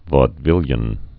(vôd-vĭlyən, vōd-, vôdə-)